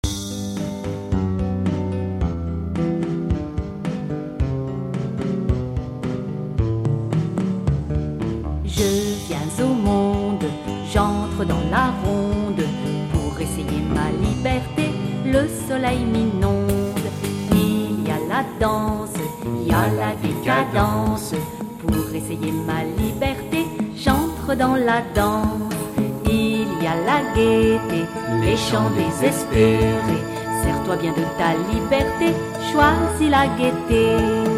SPECTACLES POUR ENFANTS : contes musicaux